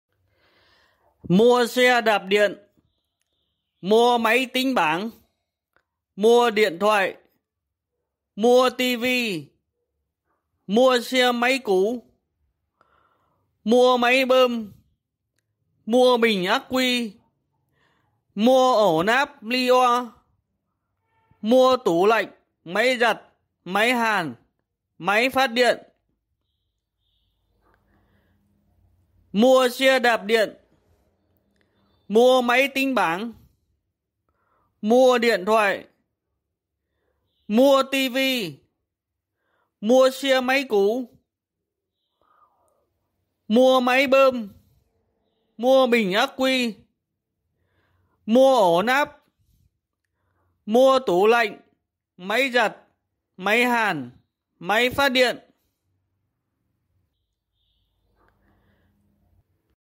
Tiếng rao mua Mua xe đạp điện, mua máy tính bảng, mua điện thoại…
Thể loại: Tiếng xe cộ
Những lời rao đều đều, lặp đi lặp lại không chỉ gợi nhắc ký ức tuổi thơ của nhiều người, mà còn phản ánh nét đặc trưng trong đời sống đô thị.
tieng-rao-mua-mua-xe-dap-dien-mua-may-tinh-bang-mua-dien-thoai-www_tiengdong_com.mp3